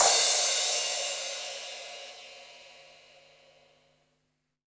Crashes & Cymbals
Crash Gold 1.wav